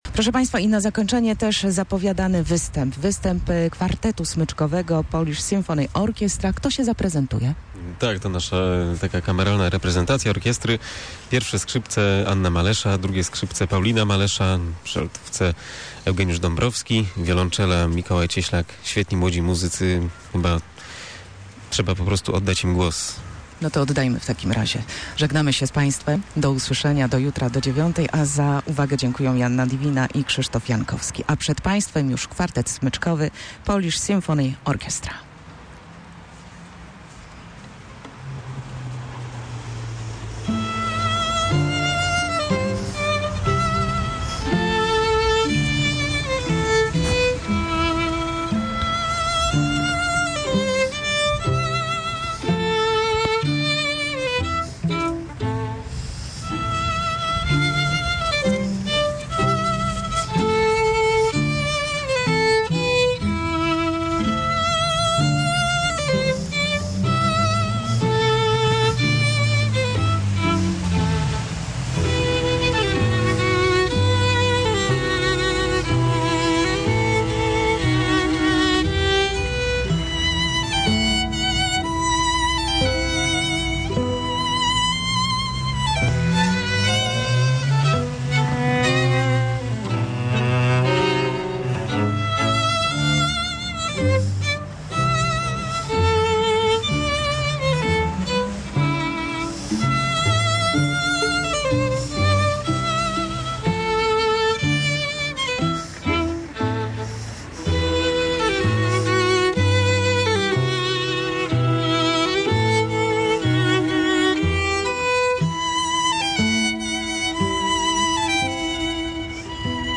Kwartet smyczkowy zagrał dla słuchaczy - Radio Poznań
Kwartet smyczkowy zagrał dla słuchaczy
Polish Symphony Orchestra (czyli Polska Orkiestra Symfoniczna) to młodzi muzycy, jedni z najlepszych, z licznymi tytułami, laureaci międzynarodowych konkursów - po prostu profesjonaliści!
Dzisiaj kwartet smyczkowy Polish Symphony Orchestra specjalnie dla Słuchaczy Radia Poznań przygotował niespodziankę.